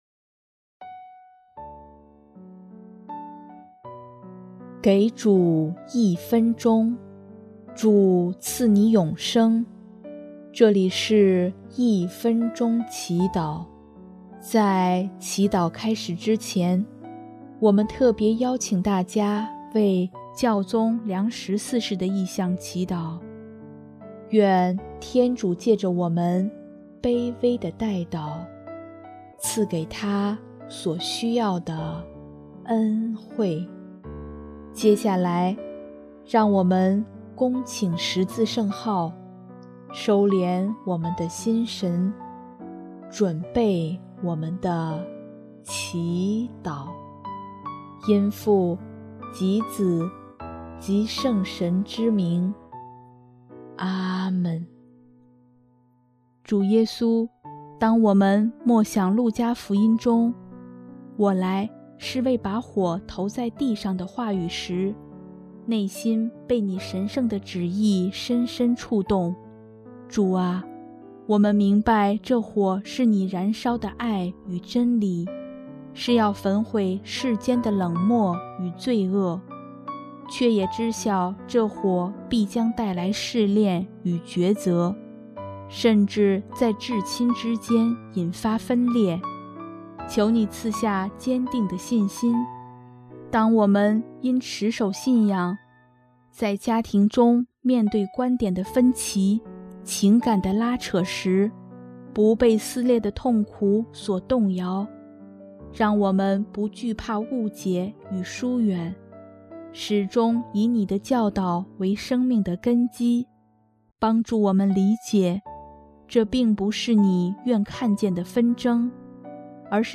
首页 / 祈祷/ 一分钟祈祷